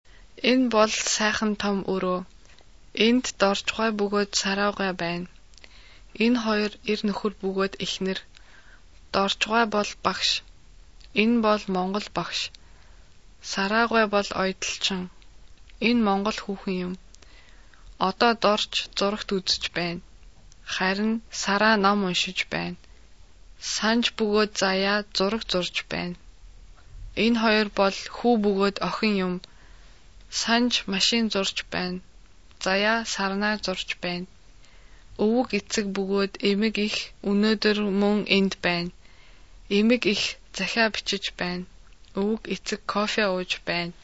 Dies ist das normale Sprechtempo!